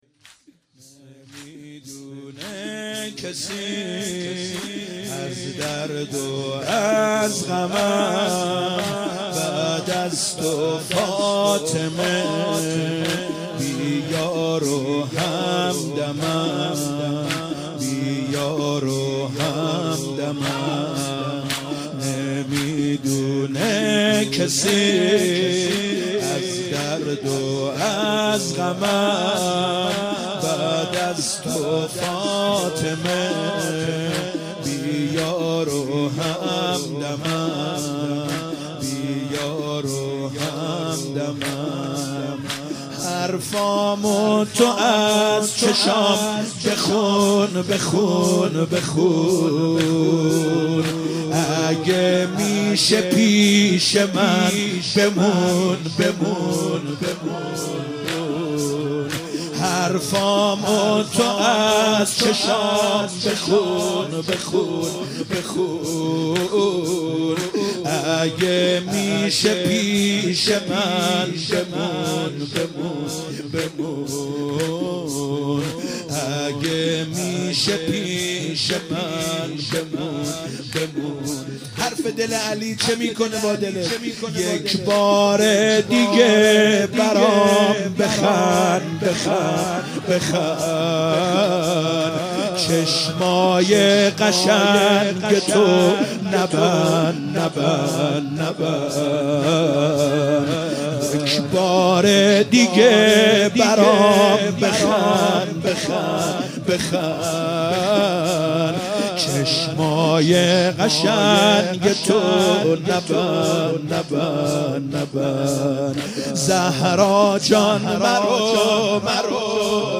فاطمیه 96 - هیئت آل یاسین - زمینه - نمی دونه کسی از درد و غمم